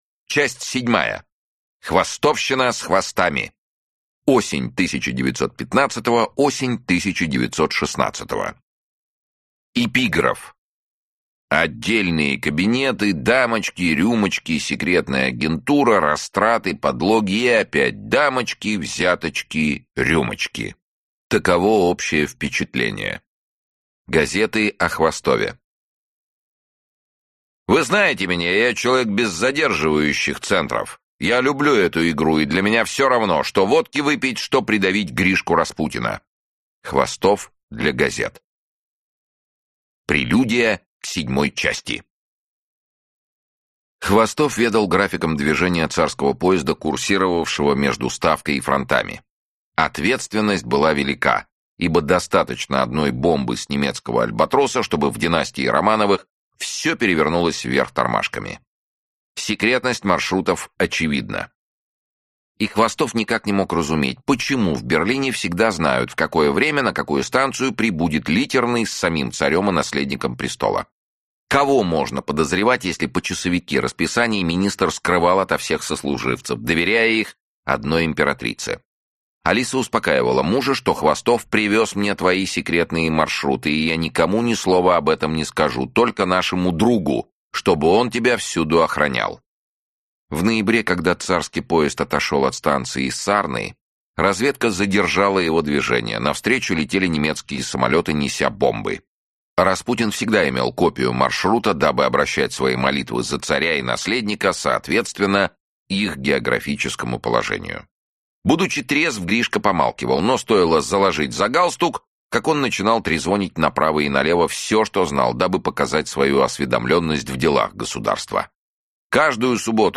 Аудиокнига Нечистая сила (часть 4-я) | Библиотека аудиокниг